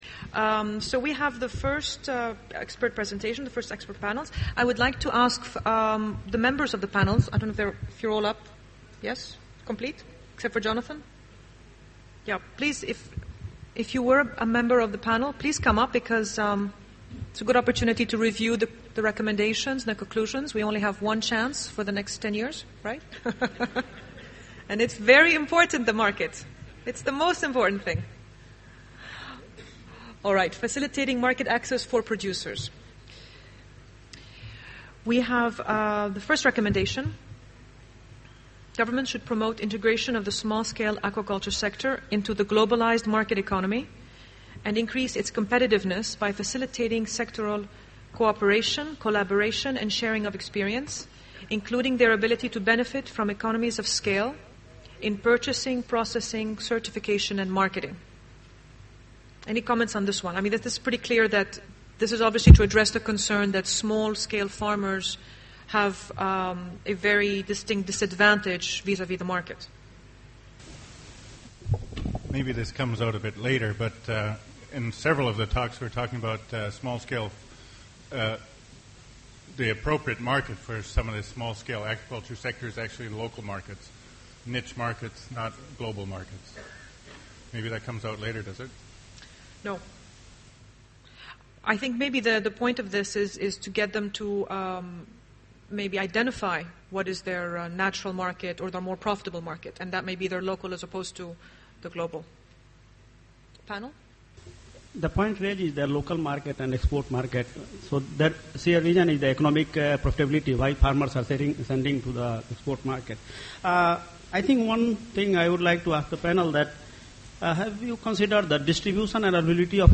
Presentation of the summary, conclusions and recommendations of Thematic Session 4 (Responding to market demands and challenges; making aquaculture a safe and diverse food producing sector for the benefit of world consumers).